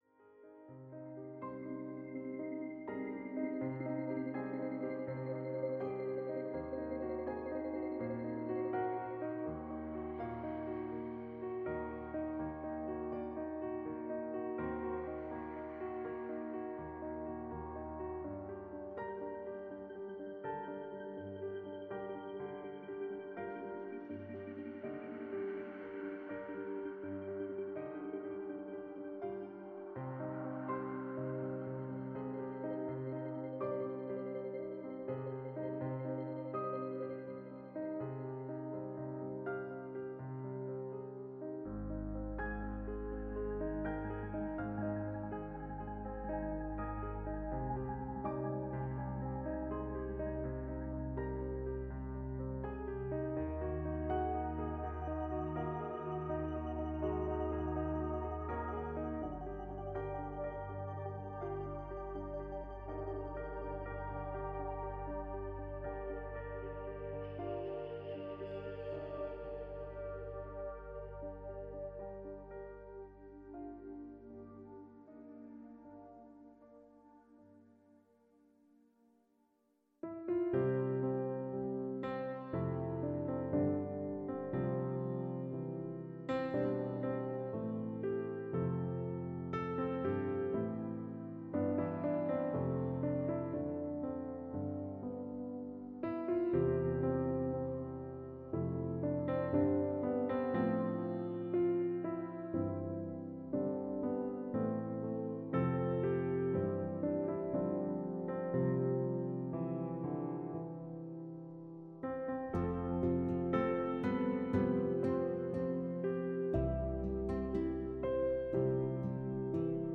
Mutfantasie - Elke Bräunling - Hörbuch